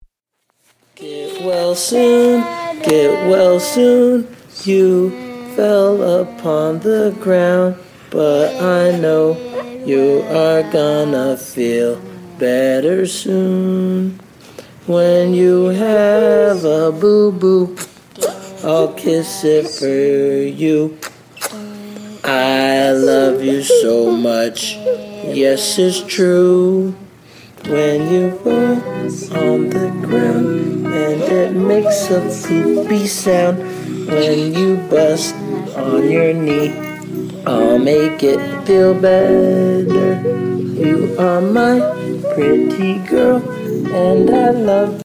Voice Memo